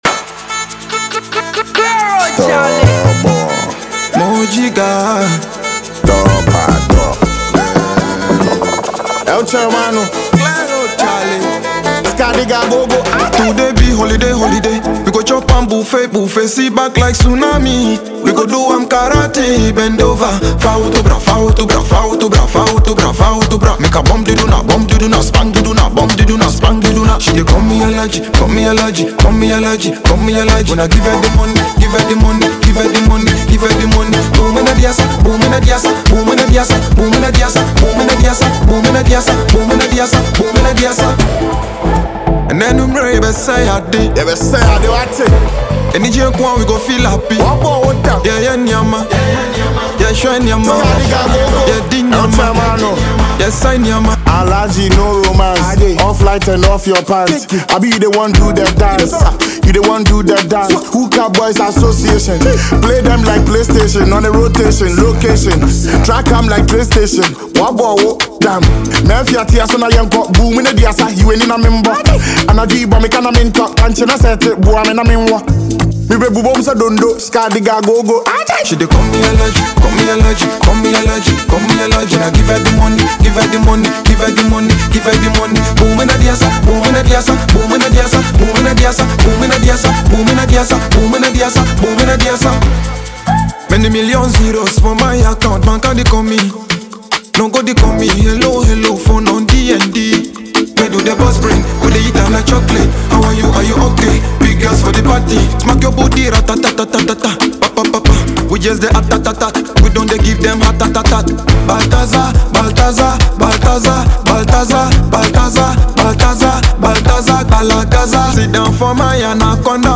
hip-hop/hiplife banger